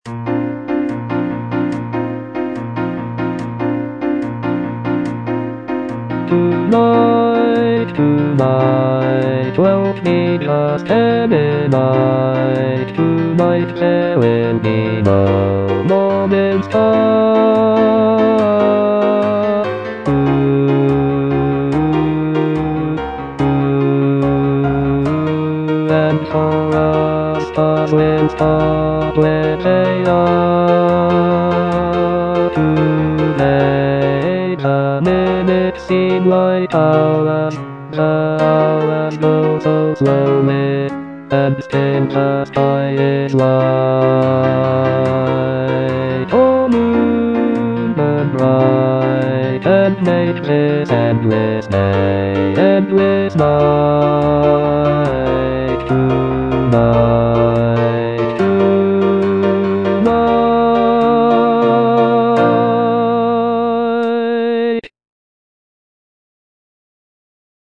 Choral selection
Bass (Voice with metronome) Ads stop